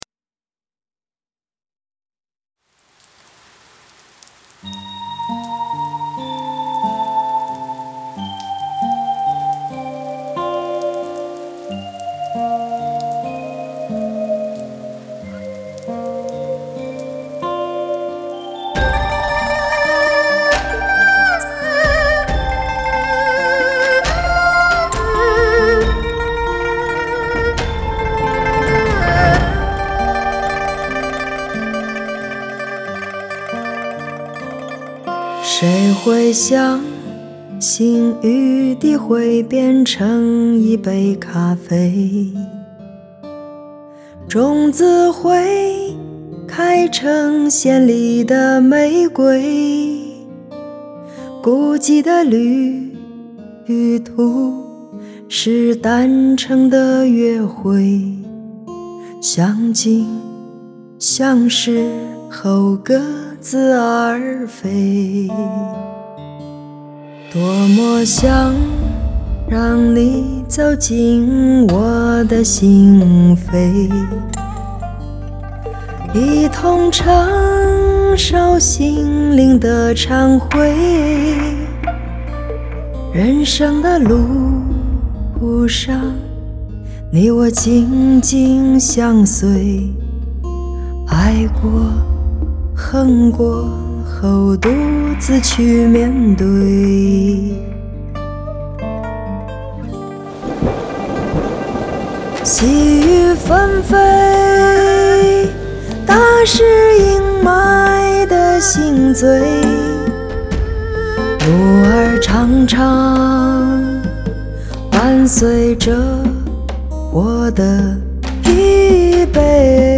类别: 摇滚